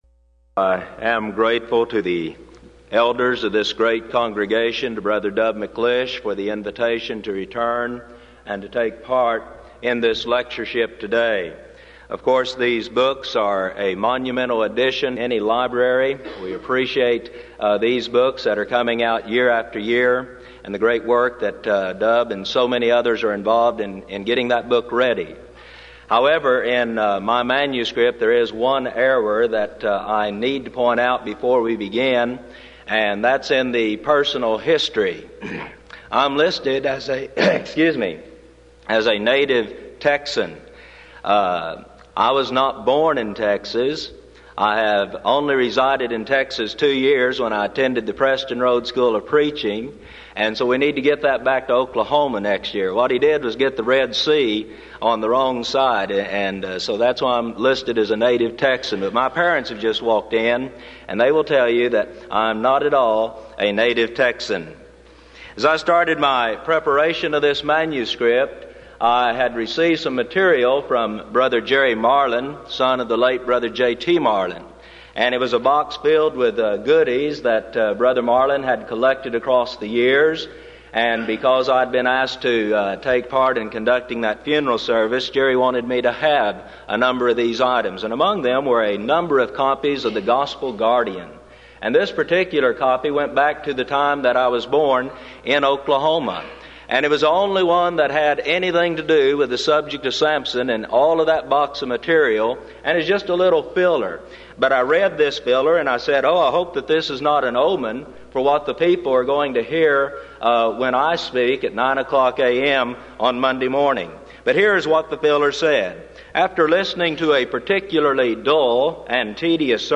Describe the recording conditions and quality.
Event: 1994 Denton Lectures Theme/Title: Studies In Joshua, Judges And Ruth